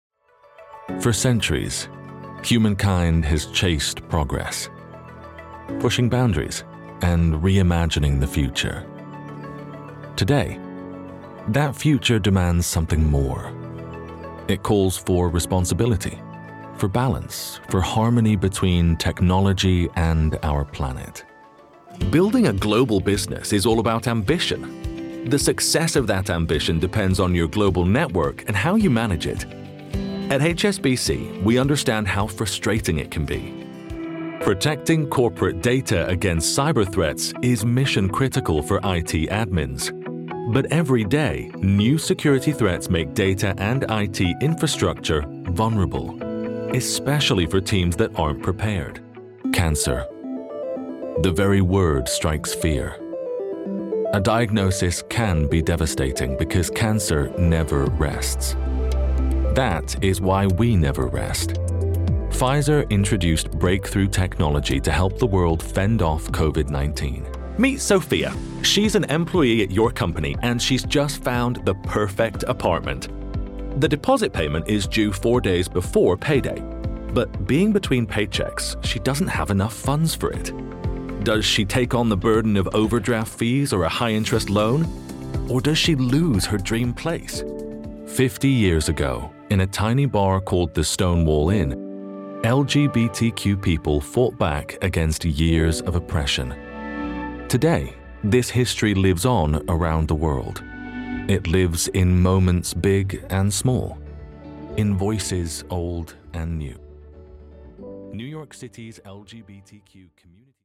Comercial, Profundo, Cálida, Seguro, Empresarial
Corporativo